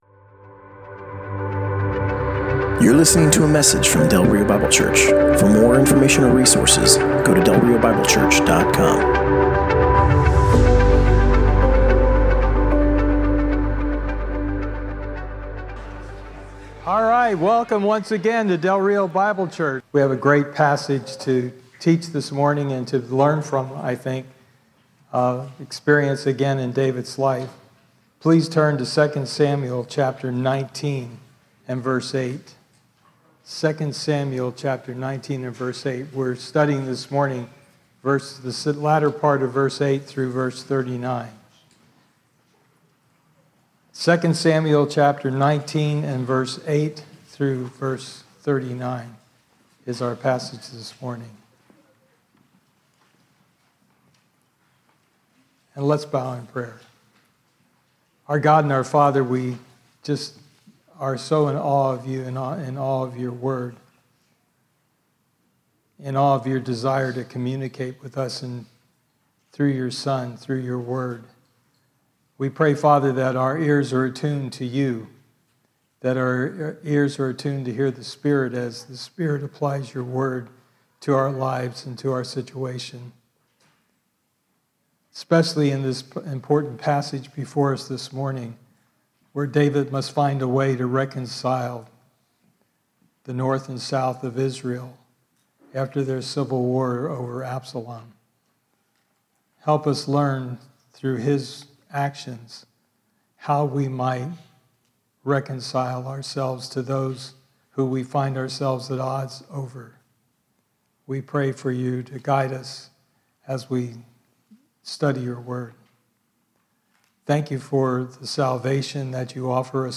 Passage: 2 Samuel 19: 8b-39 Service Type: Sunday Morning Download Files Notes « “Time to Grieve” War of Words »